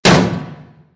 traphit_4.ogg